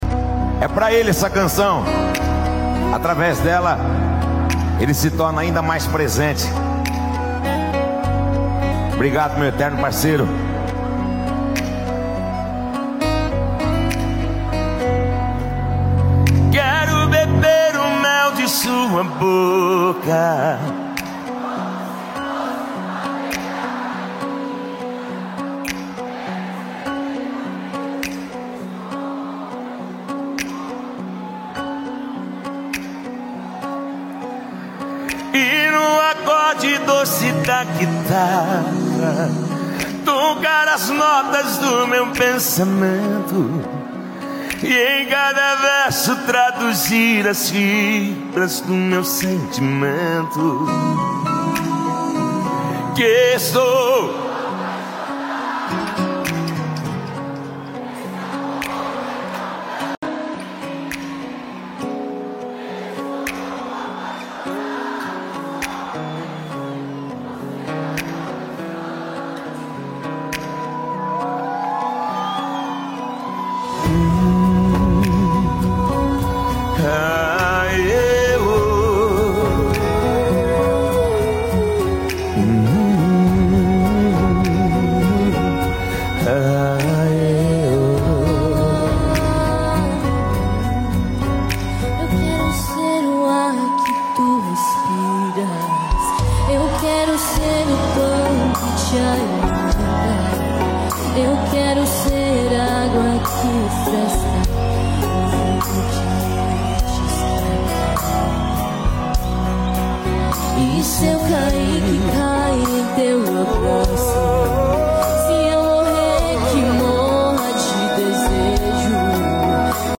Em uma noite marcada por emoção em Barretos
Sertanejo